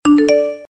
เสียงแจ้งเตือน iG (Instagram)
am-thanh-thong-bao-instagram-th-www_tiengdong_com.mp3